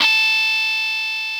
NoteA5.wav